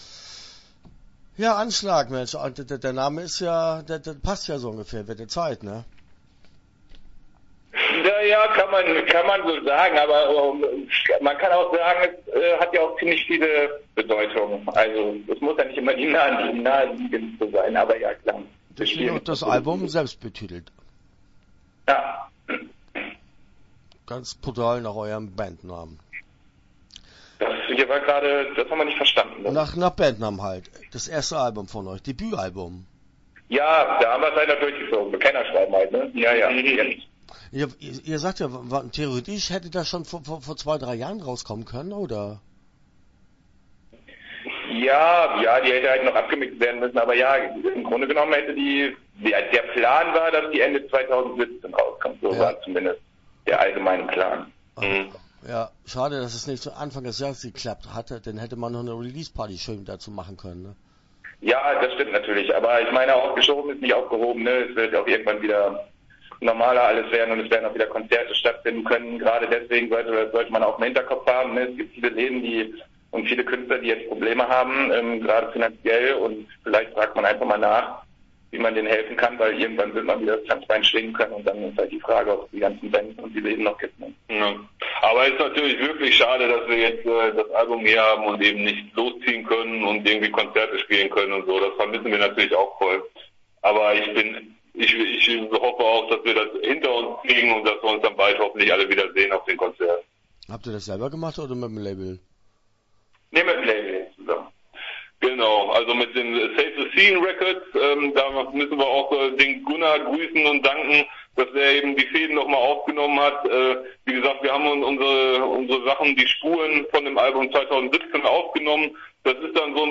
Start » Interviews » Anschlag